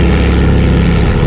wasp.wav